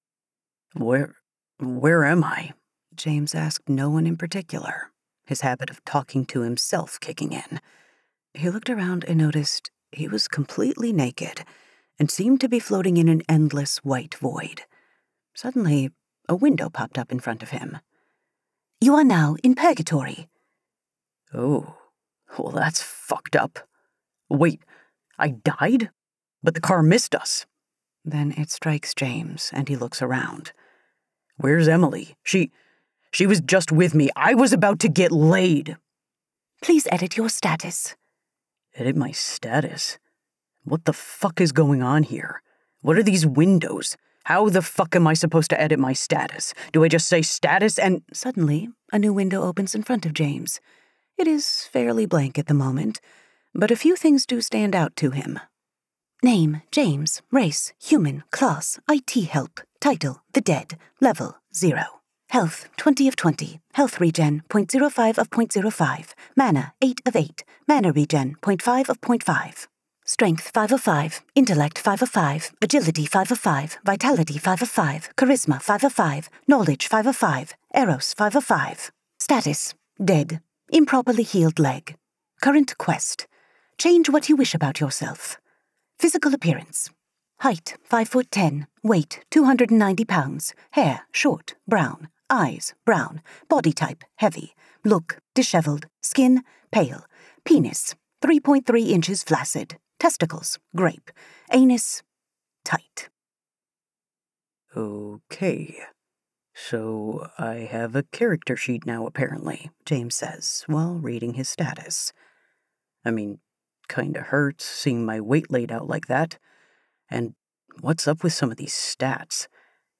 Chronicles of Lunara Audiobook
This is an audiobook, the download file you are sent after purchase contains mp3 files playable on any app that supports this.